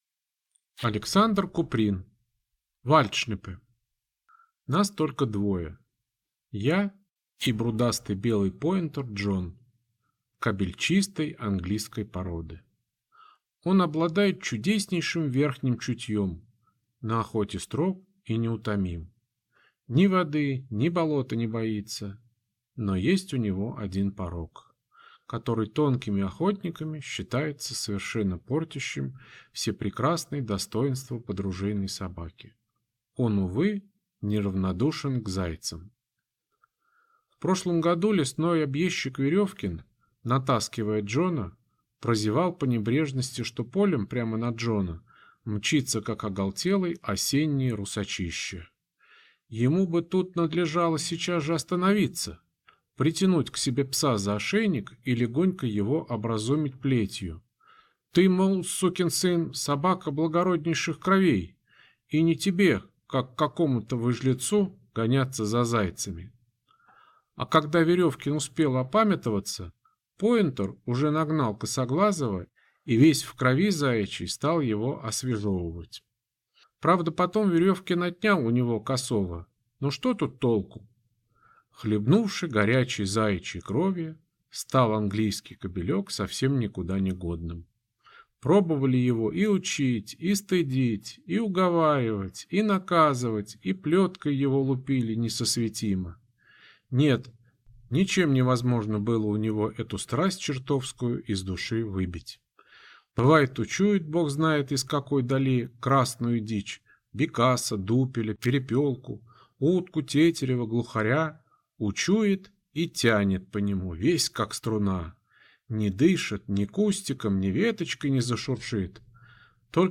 Аудиокнига Вальдшнепы | Библиотека аудиокниг